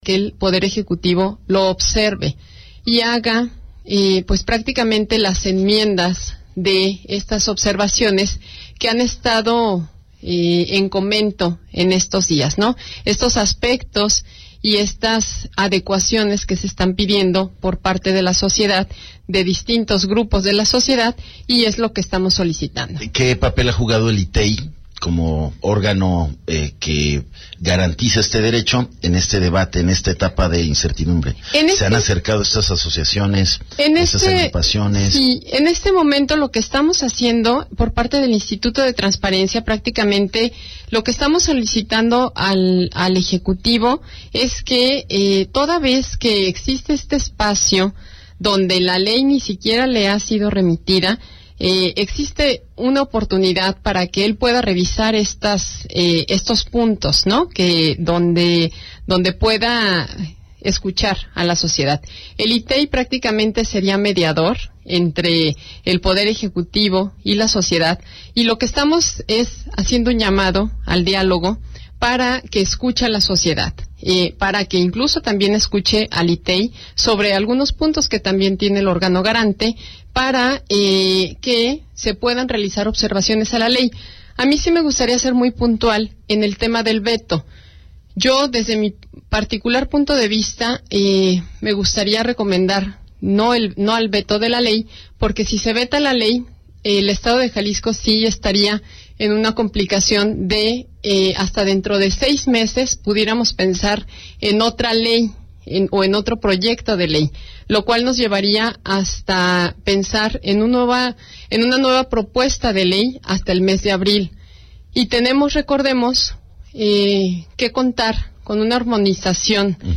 ENTREVISTA 210915